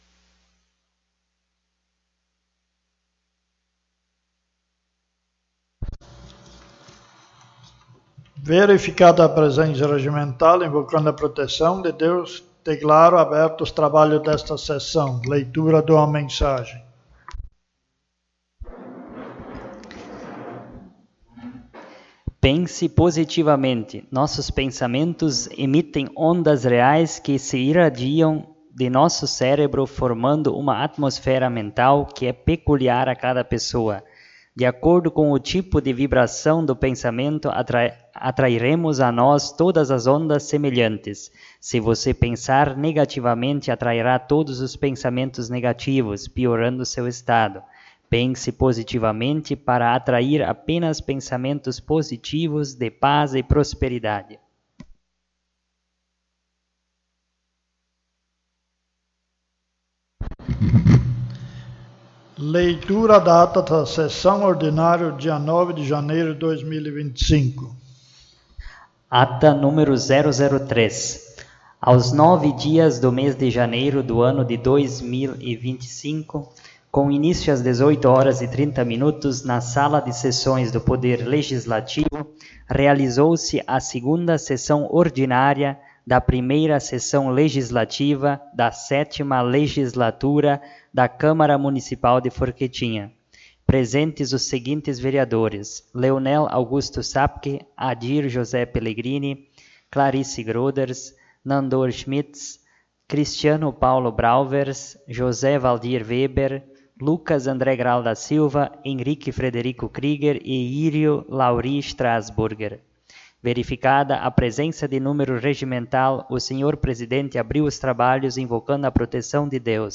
3ª Sessão Ordinária
O espaço da tribuna foi utilizado pelo vereador José Valdir Weber.